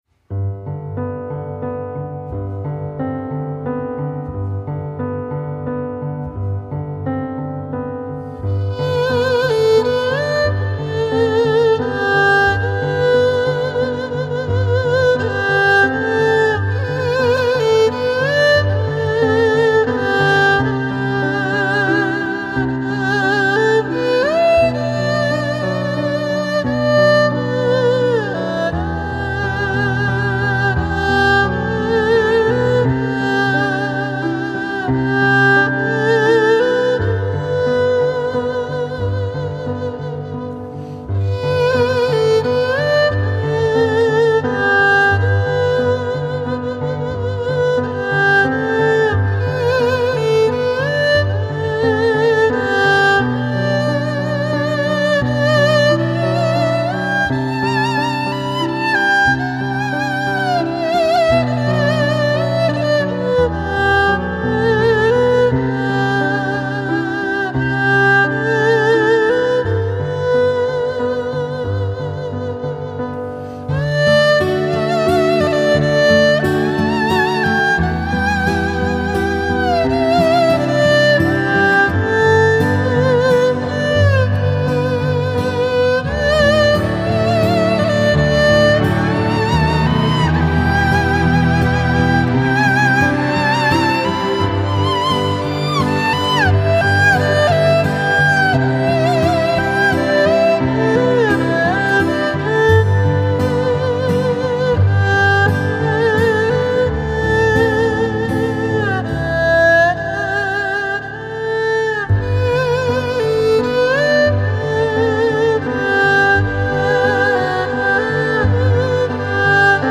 无尽的感怀从二胡揉弦中弥漫开来